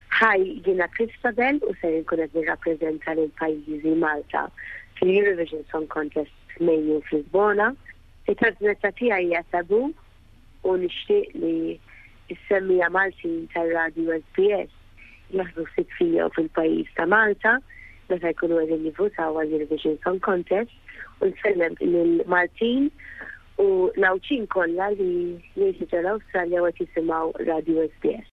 Christabelle Borg se tirrappreżenta lil Malta fil-Eurovision Song Contest, meta tkanta l-kanzunetta Taboo fit-tieni semi finali, f’Lisbona fl-10 ta’ Mejju. Dan huwa l-messaġġ ta’ Christabelle lill-Maltin u l-Għawdxin tal-Awstralja li jisimgħu l-programm Malti tal-SBS.